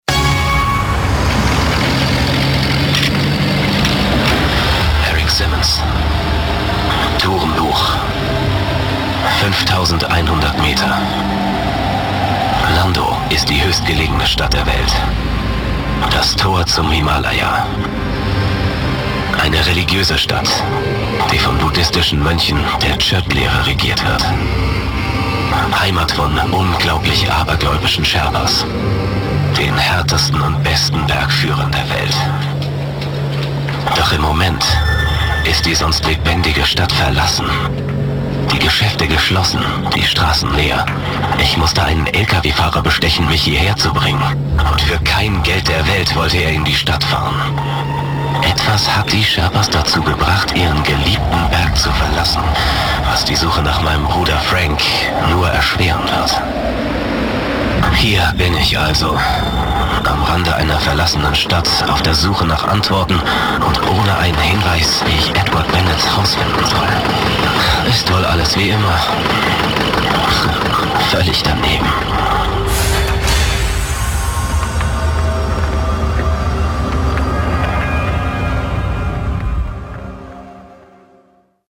Flexibel von jung/dynamisch bis seriös/offig.
Sprechprobe: Sonstiges (Muttersprache):